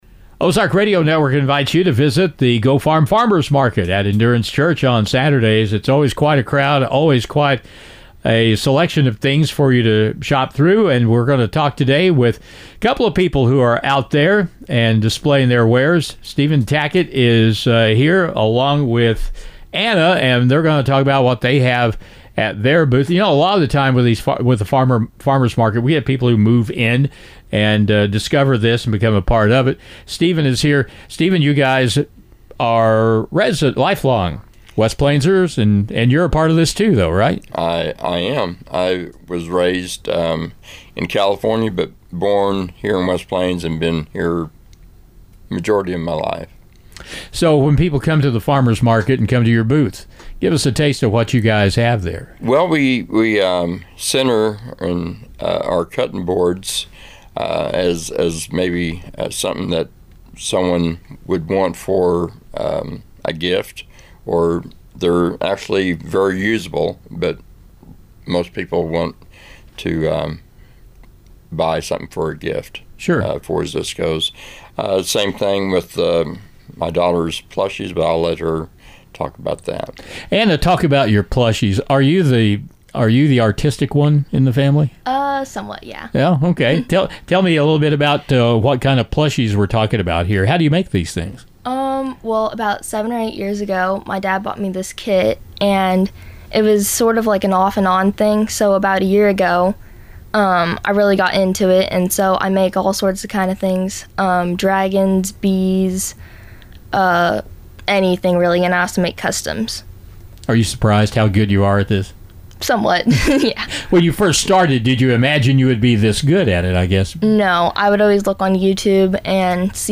West Plains, MO. – It’s Friday and time for another spotlight for the GO FARM Farmer’s Market in West Plains.